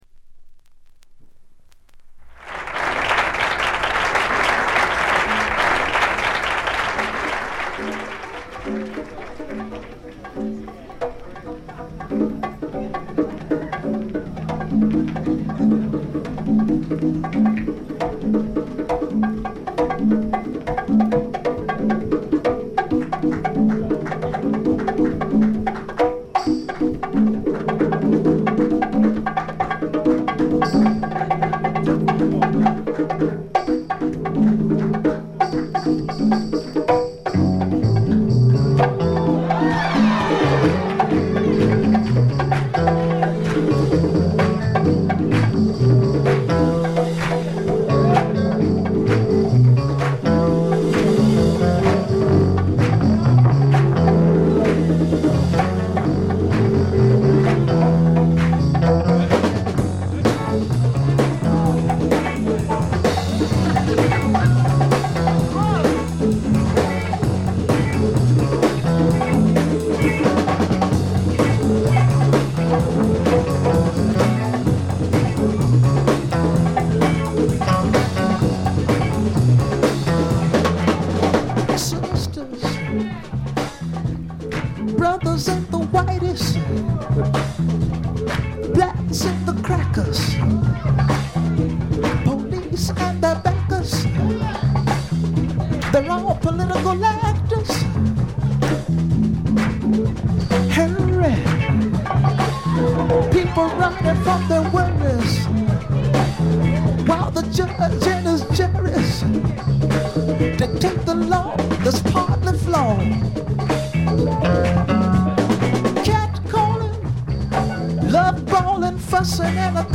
部分試聴ですがほとんどノイズ感無し。極めて良好に鑑賞できると思います。実際の音源を参考にしてください。
試聴曲は現品からの取り込み音源です。
Bongos, Congas, Percussion [Tumbas]